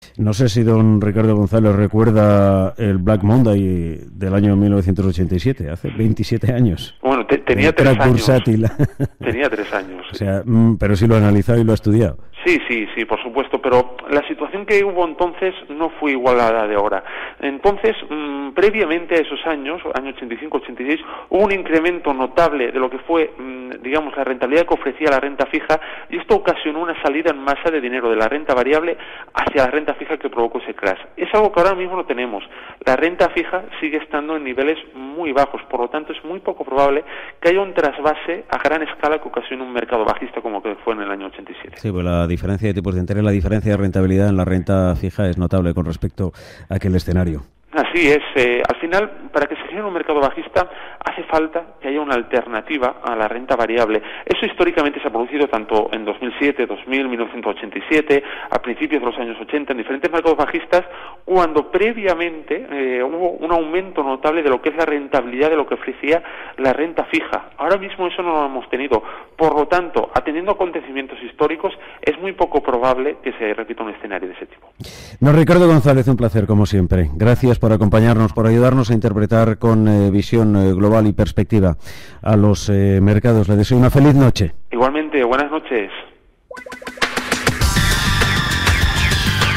Sobre este famoso crash y la situación actual, me volvieron a preguntar en Radio Intereconomía el pasado lunes, y esta fue mi respuesta: